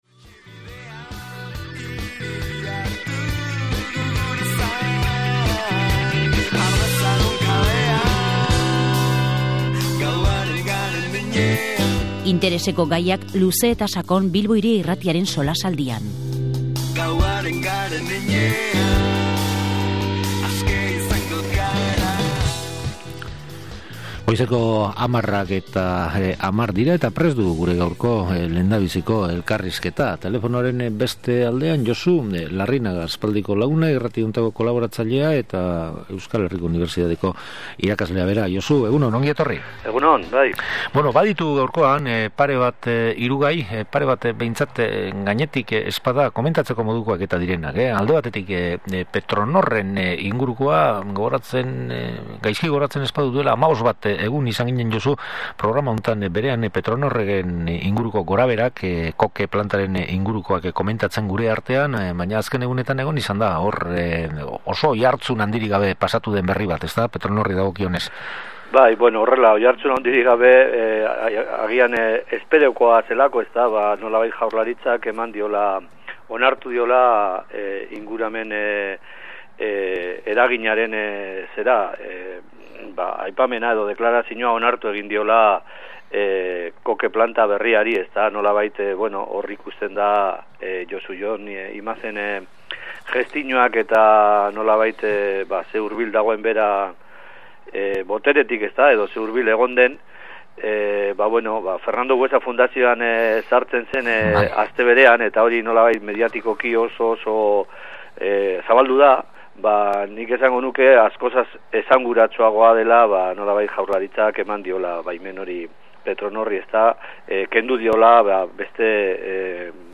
SOLASALDIA: Petronor, AHT, EHUko hauteskundeak